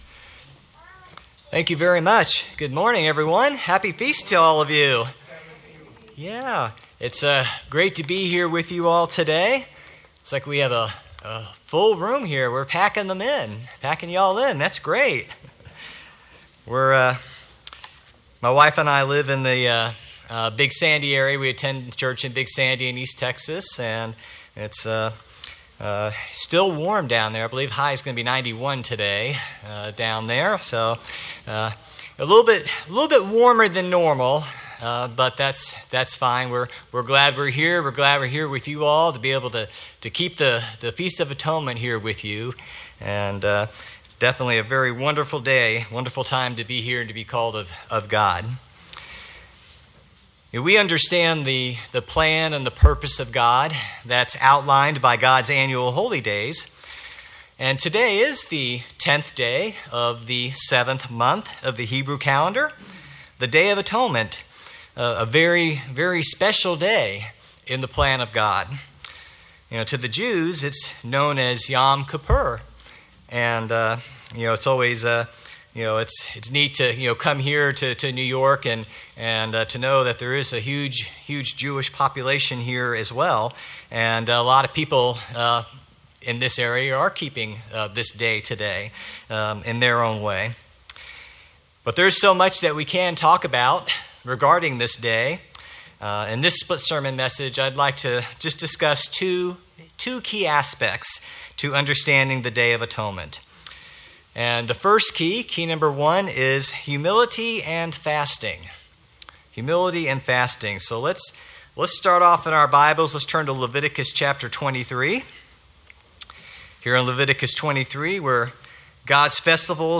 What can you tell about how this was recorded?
Given in New York City, NY New Jersey - North